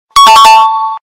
truth detector bassboosted Meme Sound Effect
truth detector bassboosted.mp3